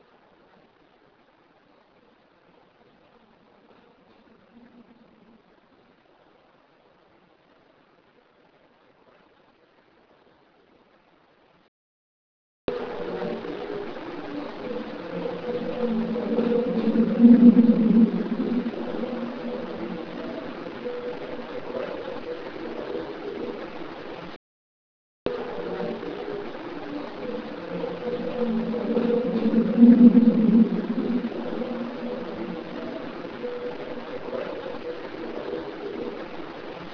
The original files are sent to IMPS in Wyoming for editing and cleaning using CoolEdit Pro 2.0 with hiss reduction (which causes odd effects in itself) and volume normalization.
Small "Varoom 2" or Probable Toilet noises - Similar to the sound above, original is quiet. Amplified and cleaned x2 after original.
Small_Varoom_2_probable_toilet.wav